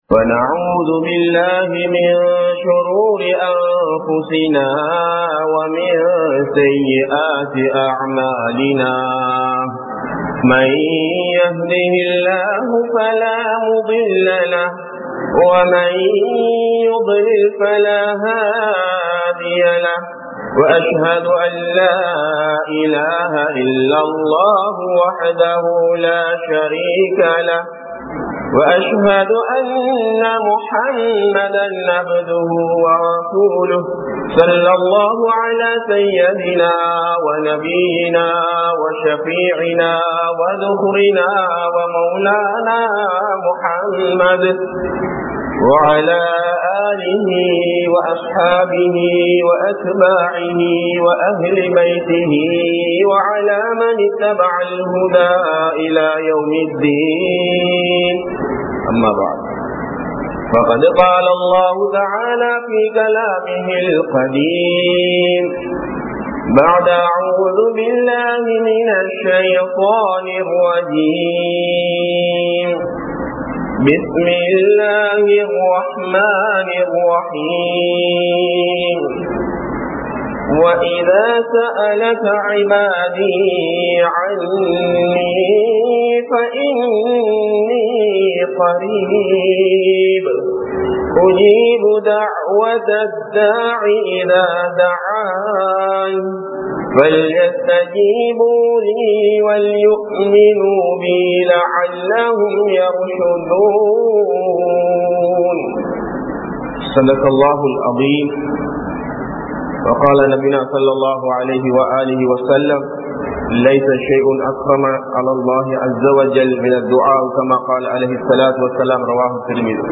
Dua vin Mukkiyaththuvam (துஆவின் முக்கியத்துவம்) | Audio Bayans | All Ceylon Muslim Youth Community | Addalaichenai